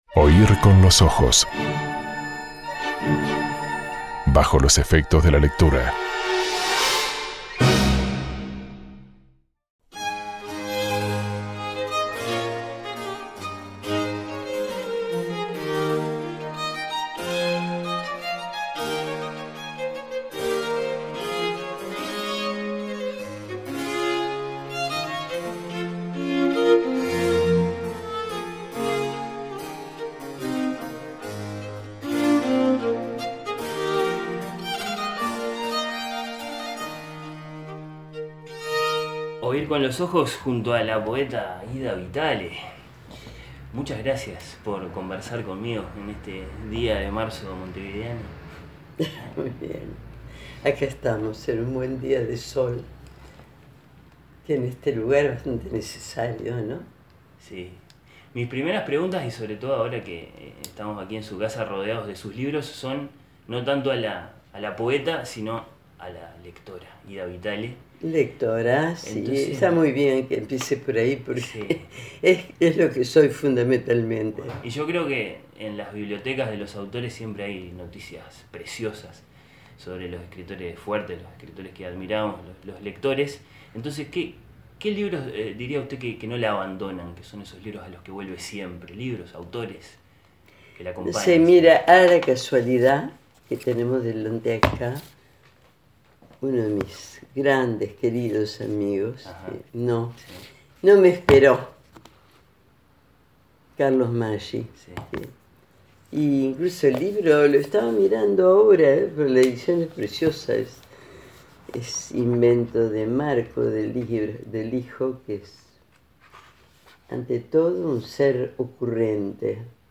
Reinstalada en Montevideo, luego de muchos años de vivir en Austin, Texas, rodeada de bibliotecas llenas de libros en nuestro idioma, en inglés y en francés (faltan muchos más todavía, que tienen que llegar desde Estados Unidos), rodeada de discos también, casi todos clásicos, Ida Vitale dialoga sobre literatura y música con palabras tranquilas, luminosas, libres de toda superstición.